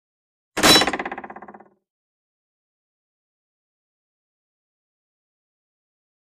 Stab Wood | Sneak On The Lot
Knife Into Wood; Knife Hits Solid Object With Metal Into Wood Impact And Spring-like Sound As The Knife Settles Into Place. Close Perspective.